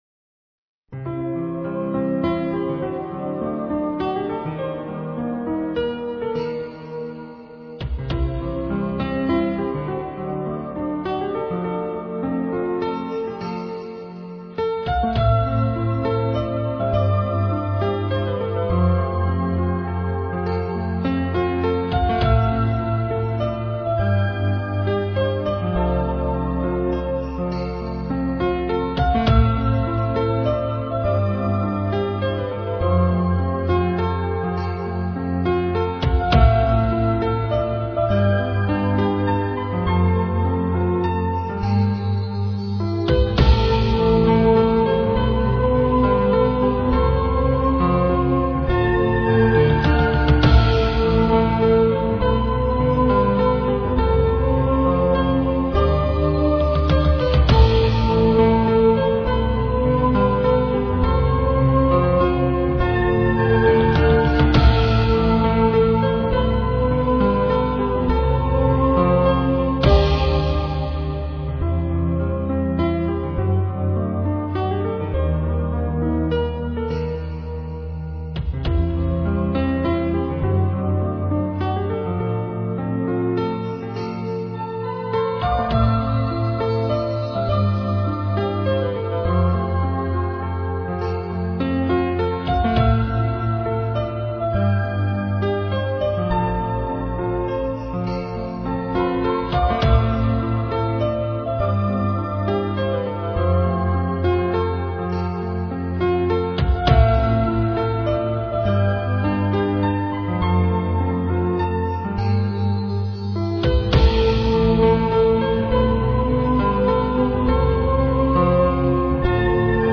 音乐+茶道＝心旷神怡。